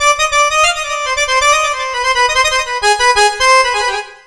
Index of /90_sSampleCDs/USB Soundscan vol.43 - ArabianTraditions [AKAI] 1CD/Partition D/08-ARABICSYN
SYNTH A 2 -L.wav